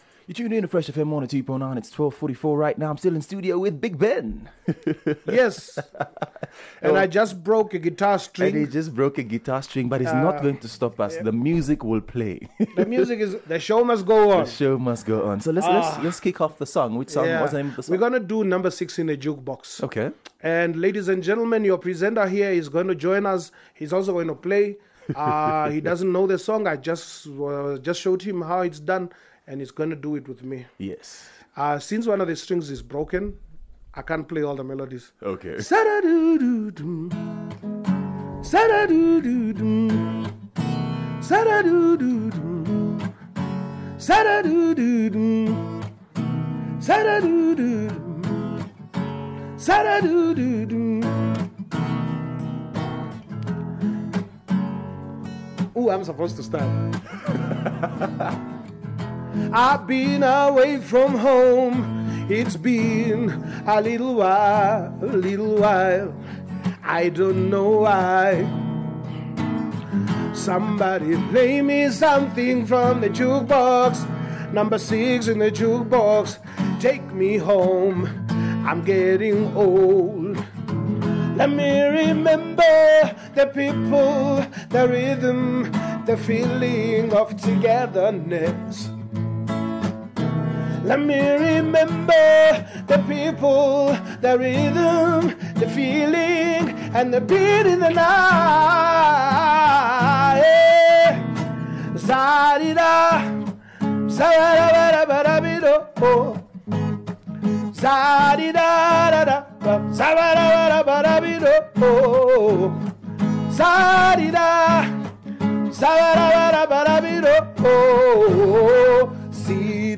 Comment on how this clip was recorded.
The Live way..